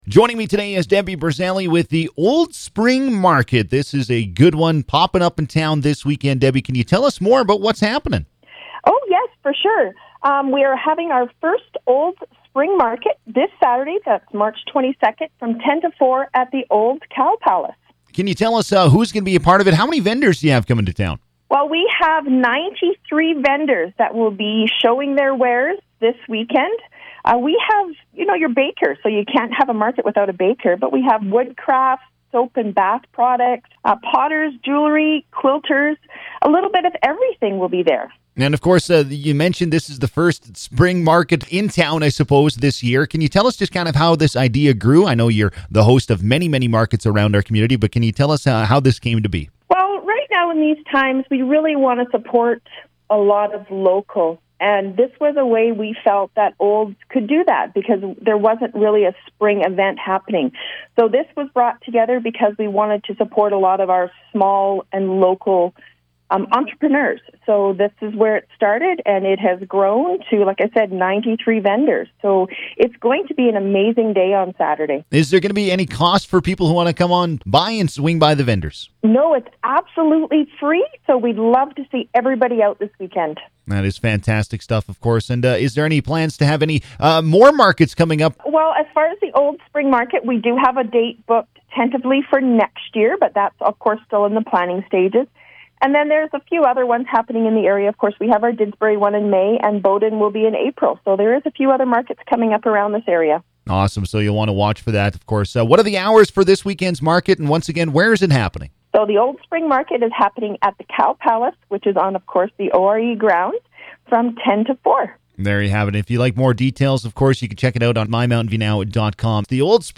Community Hotline